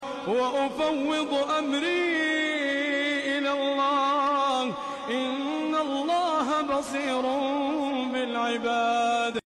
🌺تلاوة خاشعة🌺